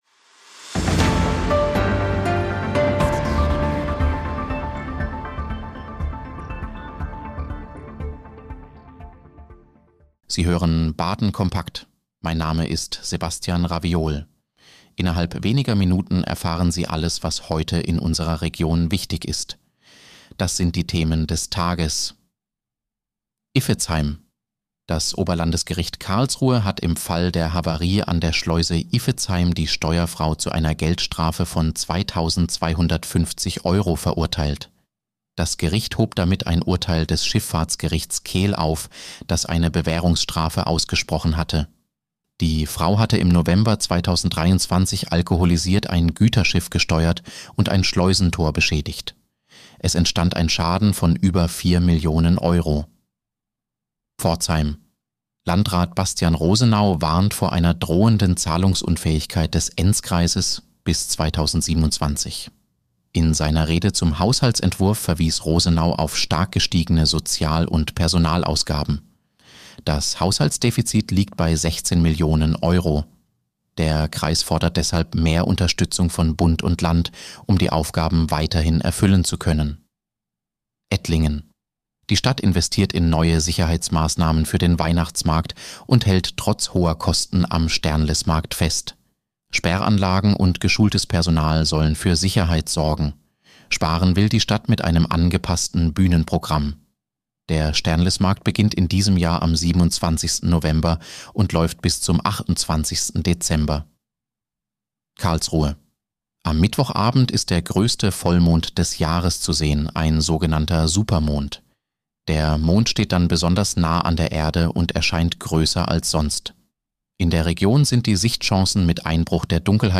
Nachrichtenüberblick Dienstag, 4. November 2025